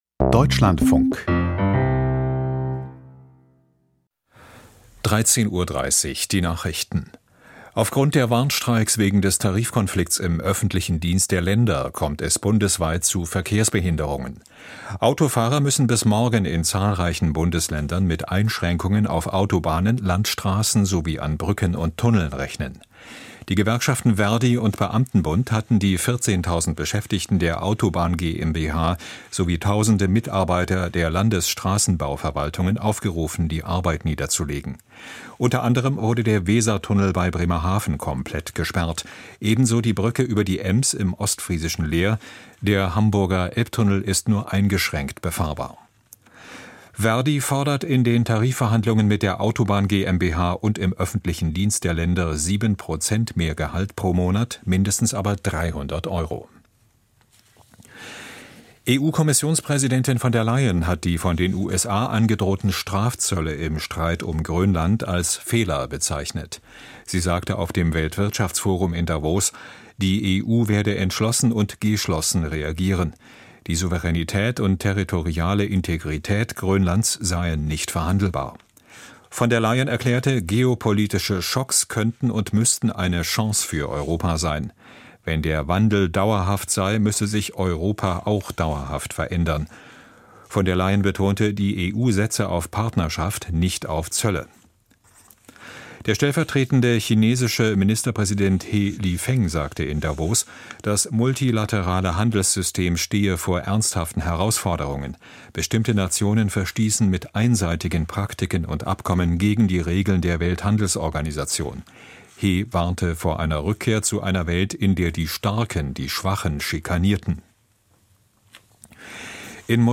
Die Nachrichten vom 20.01.2026, 13:30 Uhr
Aus der Deutschlandfunk-Nachrichtenredaktion.